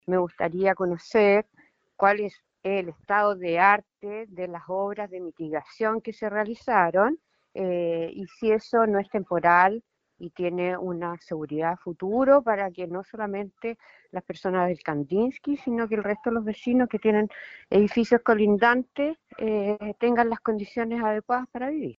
En este contexto, la concejala, Antonella Pecchenino, valoró el levantamiento de la inhabitabilidad, subrayando la seguridad no solo del edificio Kandinsky, sino que la de todos los inmuebles afectados.
cu-kandinsky-concejal-antonella-pecchenino-1.mp3